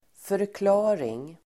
Uttal: [förkl'a:ring]